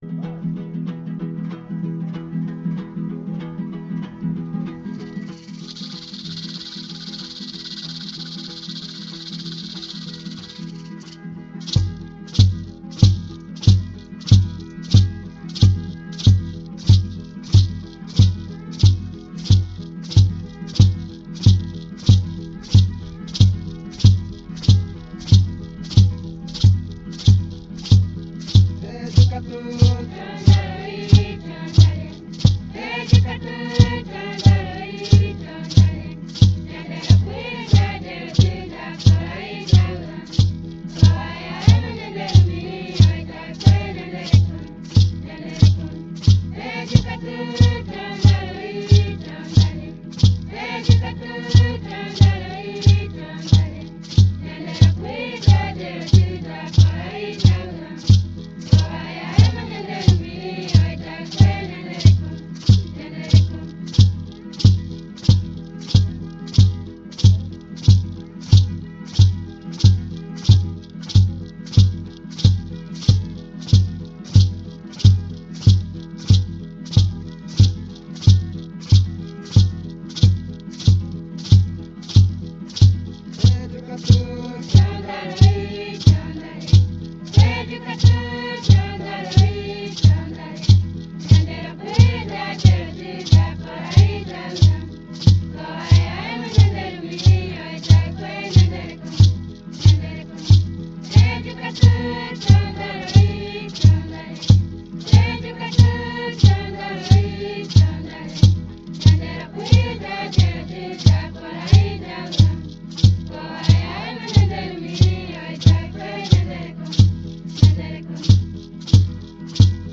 Pejukatu Xondaro’l  – Coral Guarani Tenonderã